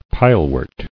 [pile·wort]